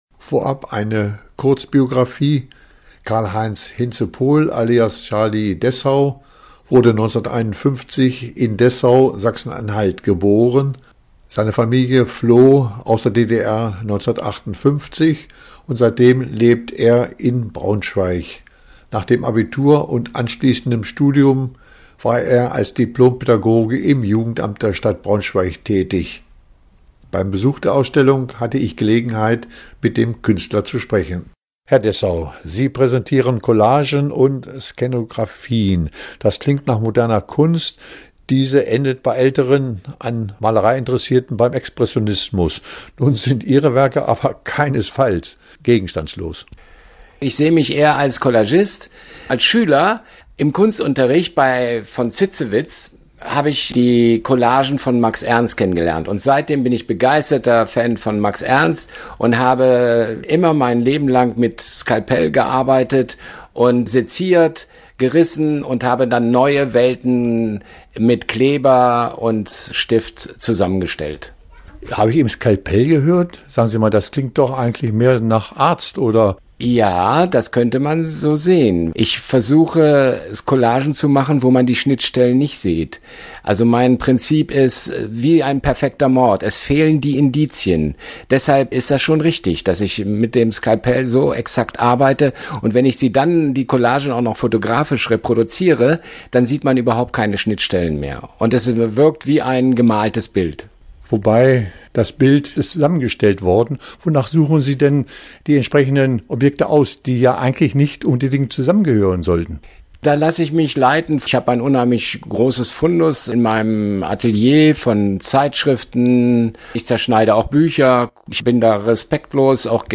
Interview Dessau-Ausstellung
Interview-Dessau-Ausstellung.mp3